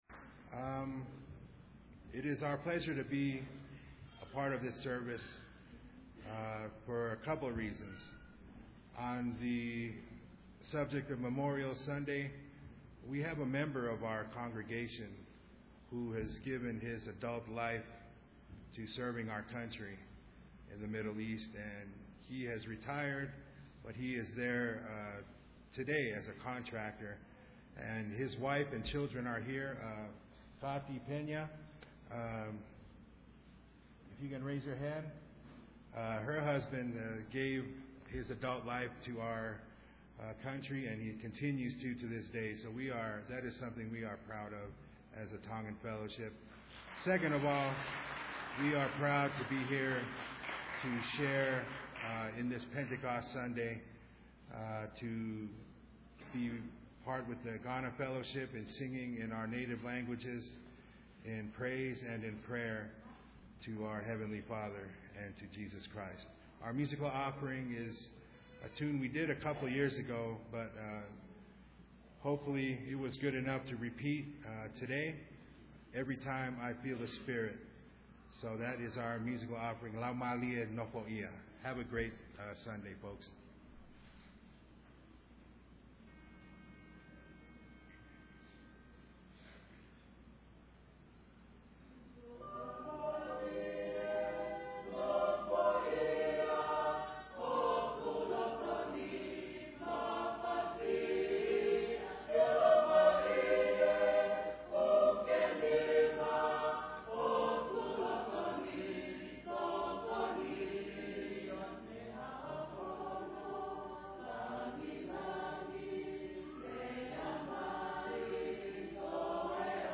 Pentecost Sunday Worship Service
Tukulolo"                     Tonga Fellowship Choir